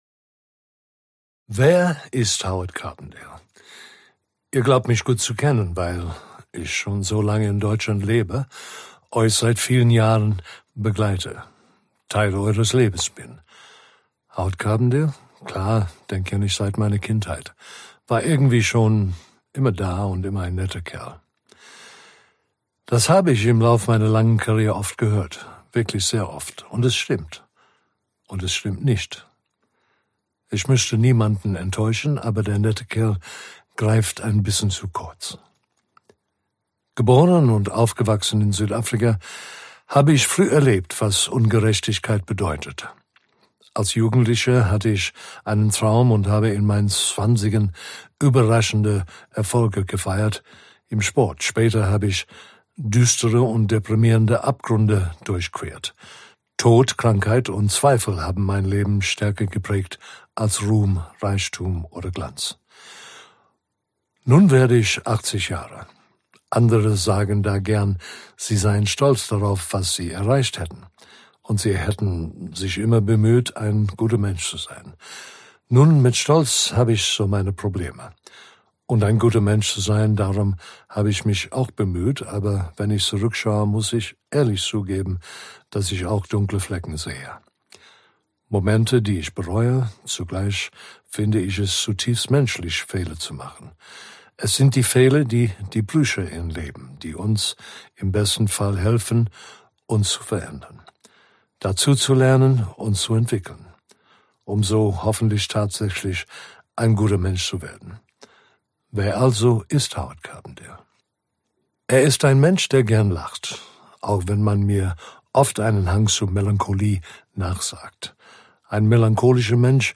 2025 | Ungekürzte Lesung